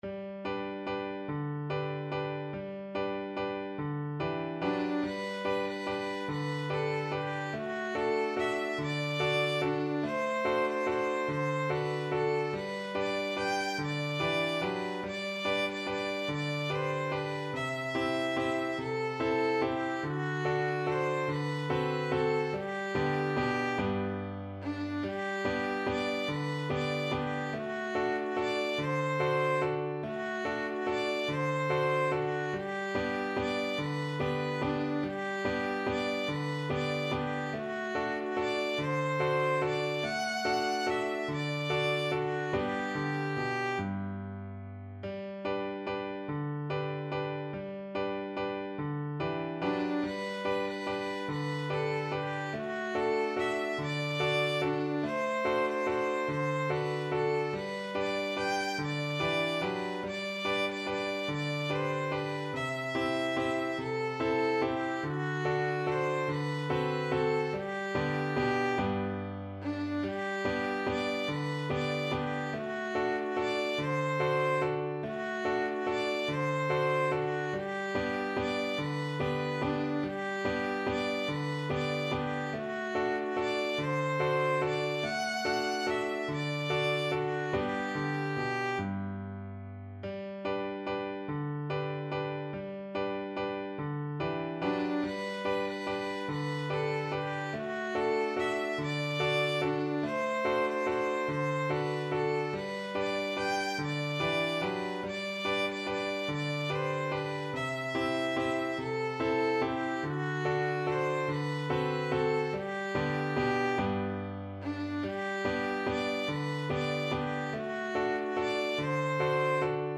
Violin version
3/8 (View more 3/8 Music)
Steady one in a bar .=c.48
Traditional (View more Traditional Violin Music)
Swiss